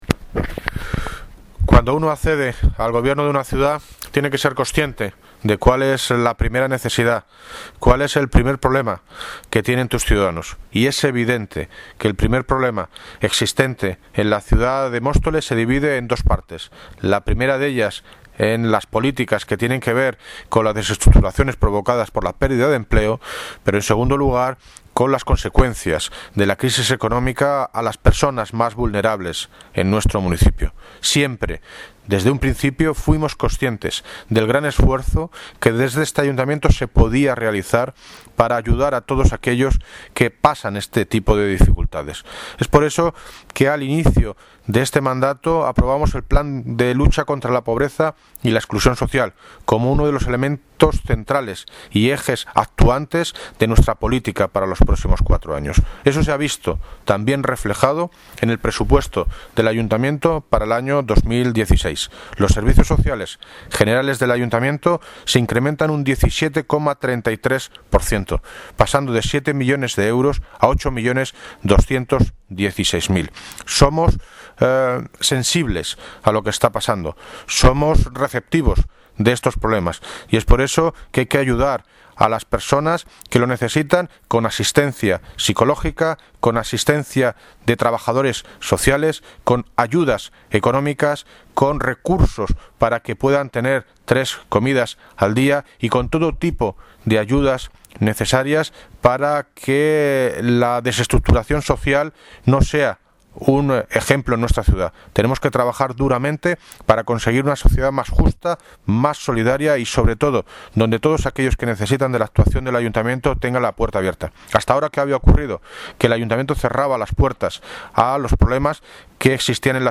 Audio - David Lucas (Alcalde de Móstoles) Sobre incremento politica social